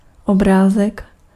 Ääntäminen
IPA: /i.maʒ/